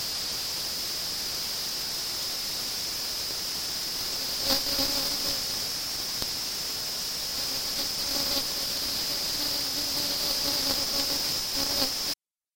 A mosquito.
mosquito.mp3